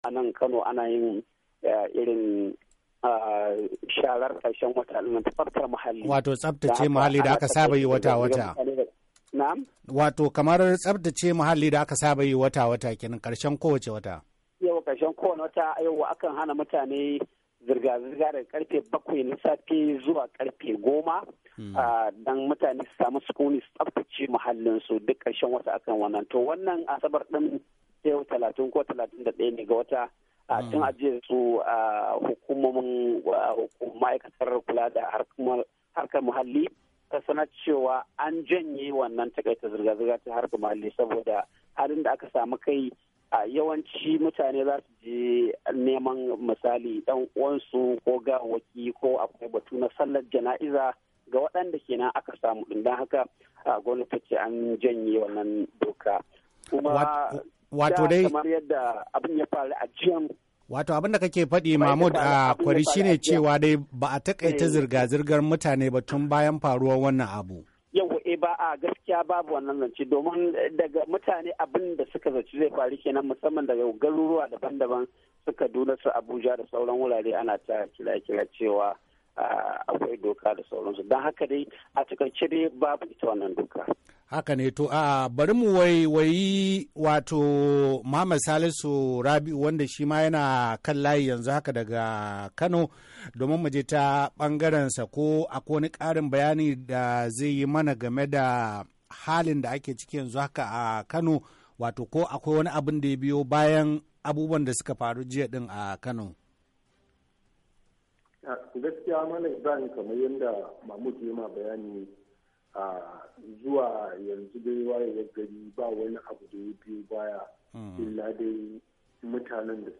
Tattaunawa Kai Tsaye Da Wakilanmu Na Kano A Safiyar Asabar A DandalinVOA - 11'15"